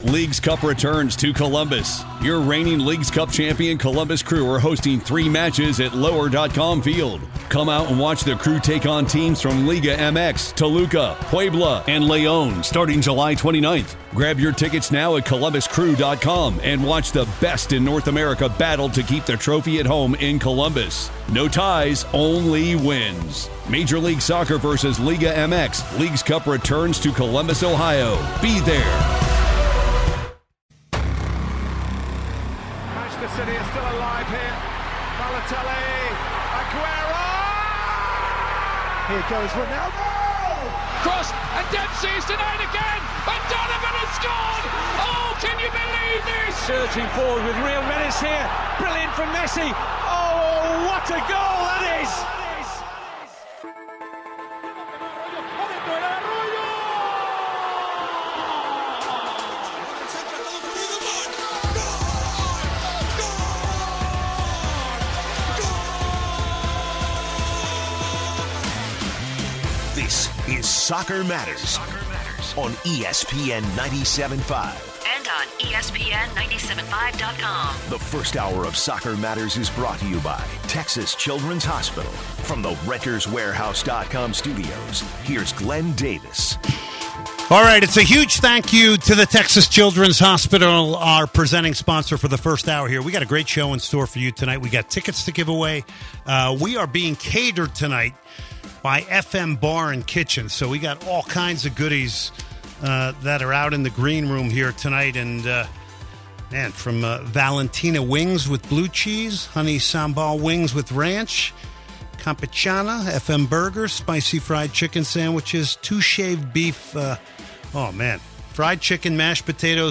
calls in and expresses concern in the Dynamo recently failing to capitalize in games, getting more draws than wins.
calls in and asks about Chelsea’s chances in the BPL this upcoming year